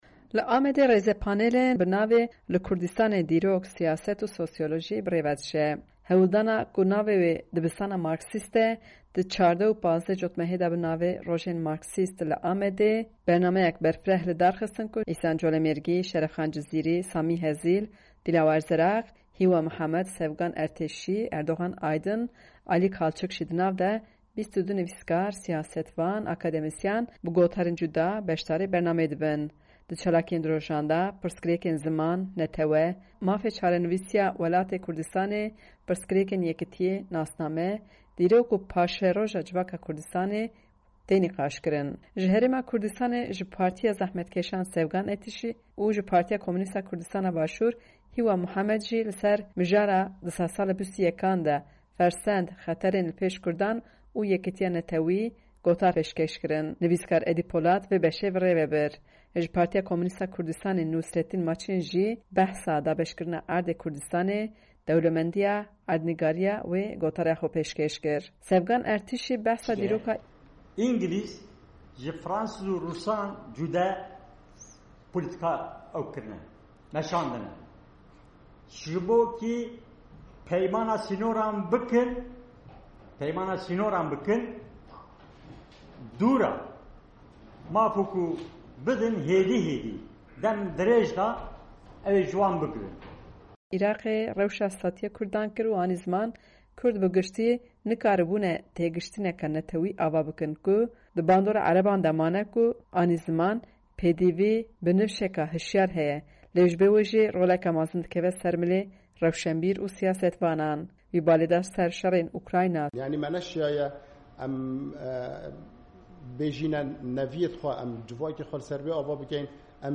Diyarbakir panel